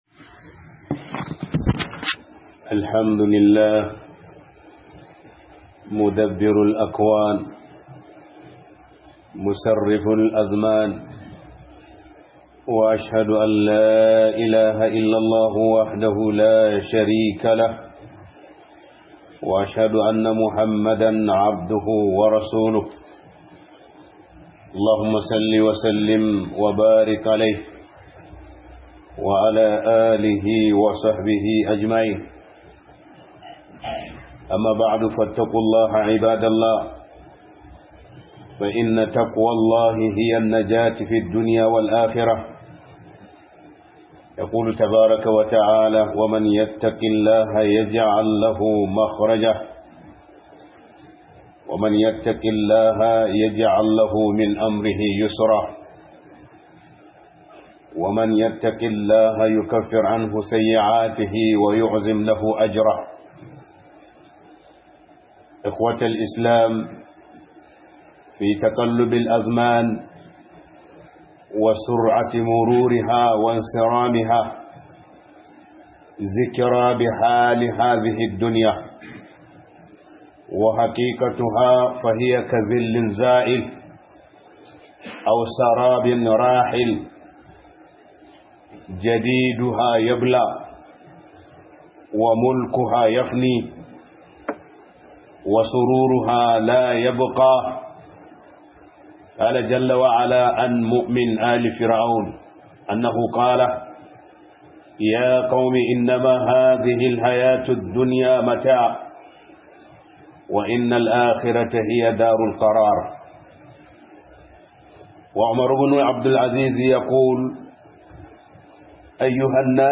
Mu guji rudin duniya - HUDUBA